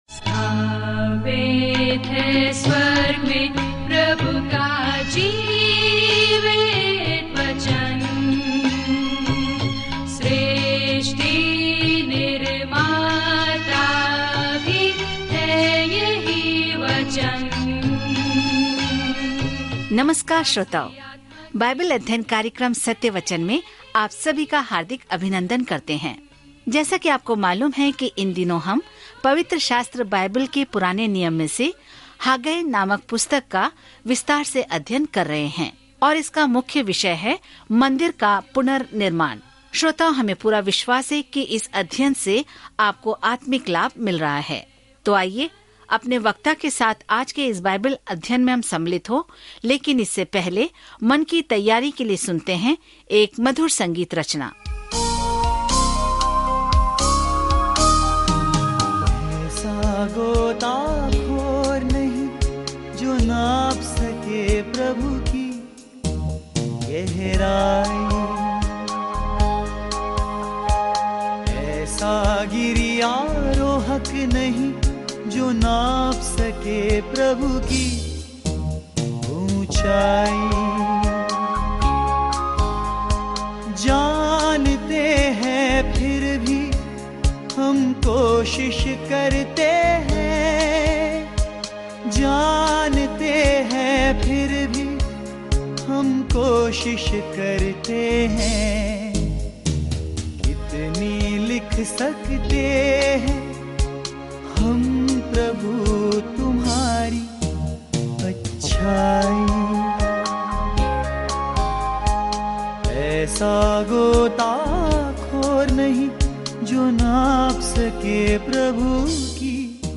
पवित्र शास्त्र हाग्गै 2:4-5 दिन 5 यह योजना प्रारंभ कीजिए दिन 7 इस योजना के बारें में हाग्गै का "इसे पूरा करो" रवैया विचलित इज़राइल से कैद से लौटने के बाद मंदिर का पुनर्निर्माण करने का आग्रह करता है। जब आप ऑडियो अध्ययन सुनते हैं और भगवान के वचन से चुनिंदा छंद पढ़ते हैं तो हाग्गै के माध्यम से दैनिक यात्रा करें।